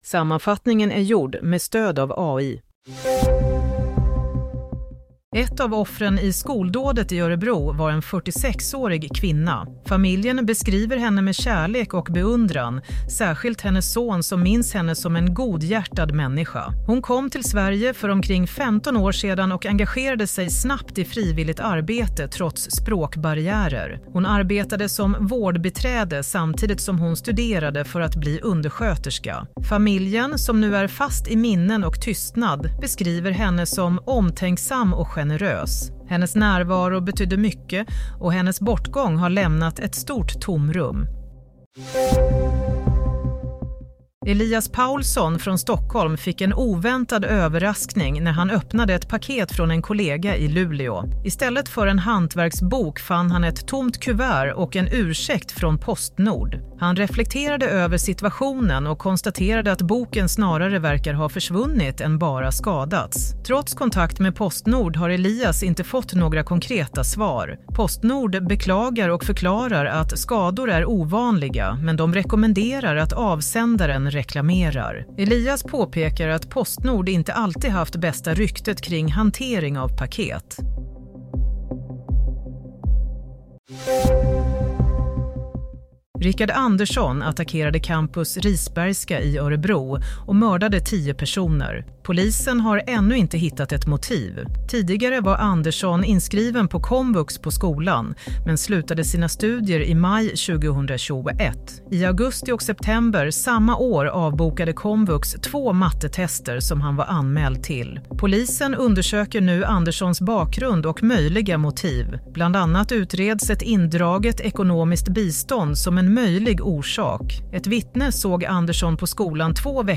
Nyhetssammanfattning 13 februari – 22.00
Sammanfattningen av följande nyheter är gjord med stöd av AI.